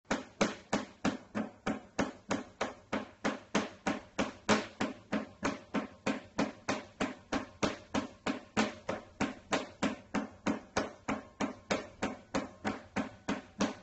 Climatiseur Mitsubishi fait un bruit ininterrompu
Ce qui est bizarre c'est que même éteint, ça continue de faire des bruits de marteau.
Hmmm.... ça ne peut pas être le bruit d'une pompe de relevage traditionnelle.
Je comprends mieux le terme "coups de marteau".
Si c'est une pompe à galet alors possible que l'engrenage soit HS sur une partie d’où les coups réguliers.
bruit-climatiseur-mitsubishi.mp3